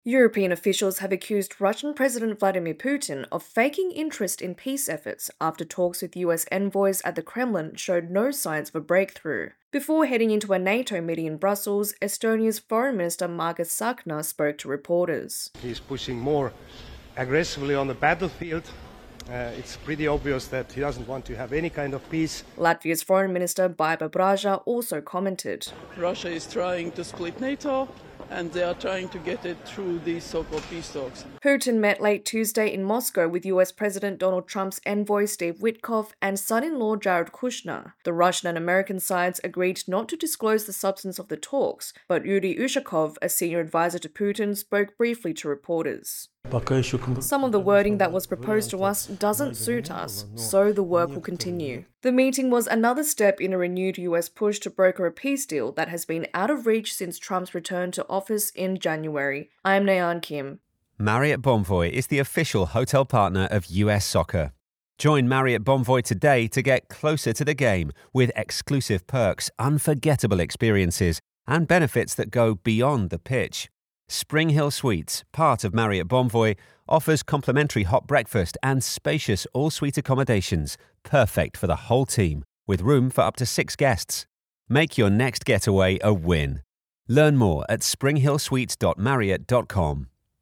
European officials are accusing Russian President Vladimir Putin of not wanting peace in Ukraine after five hours of talks with U.S. envoys at the Kremlin on Tuesday showed no breakthrough. AP correspondent